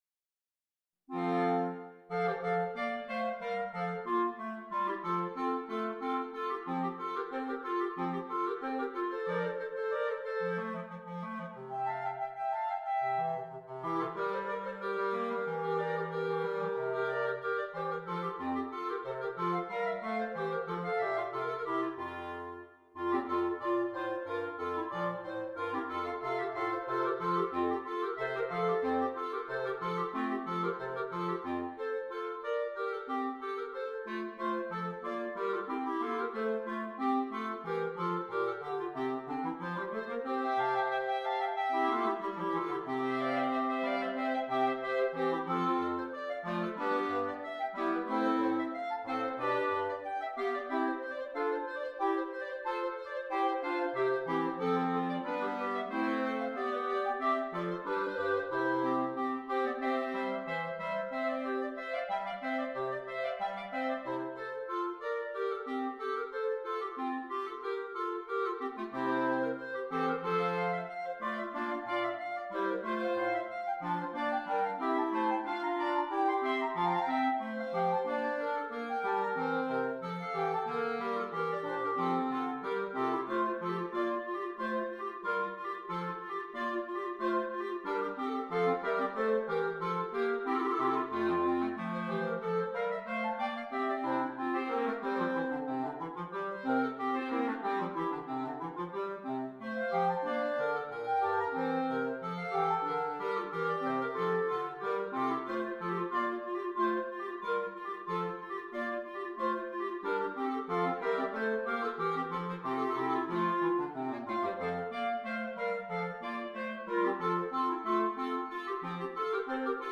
4 Clarinets, Bass Clarinet